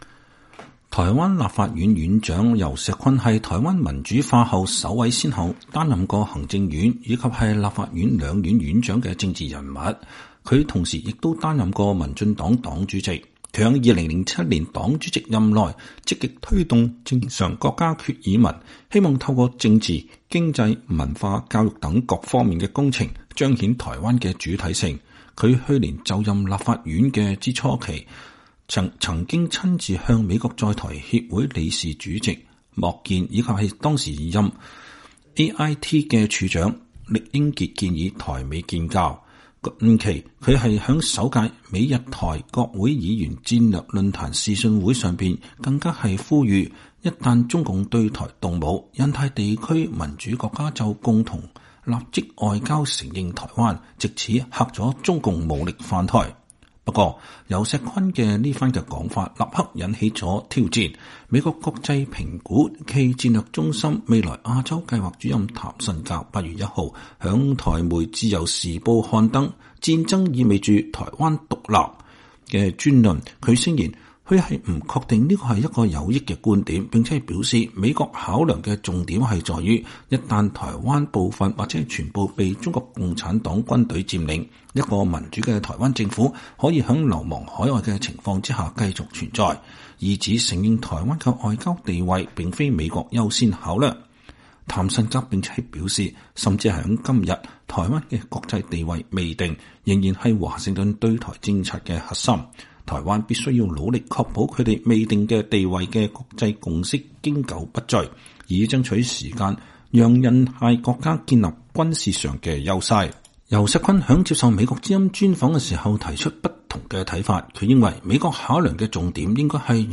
專訪游錫堃：美國“新一中政策”已經形成